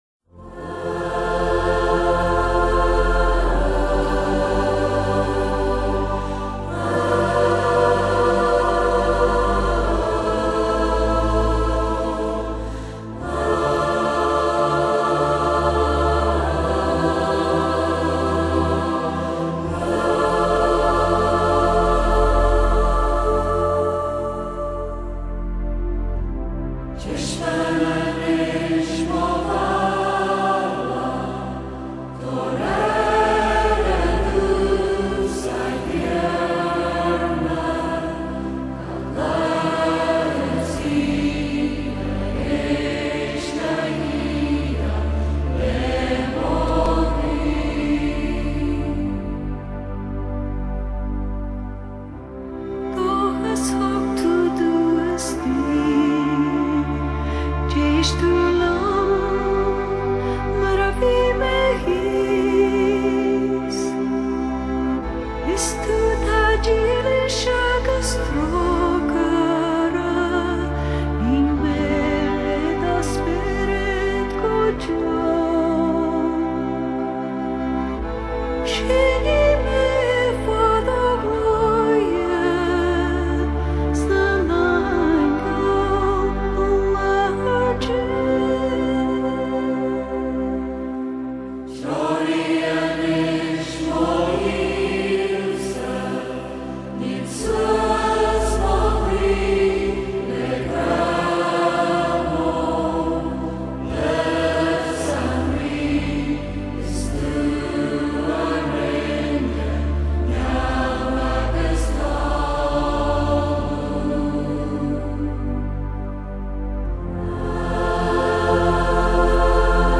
有时候忧伤中却又充满无限的希望，它适 时的捕捉了爱尔兰音乐所独有的哀愁感，这就是爱尔兰音乐的特色。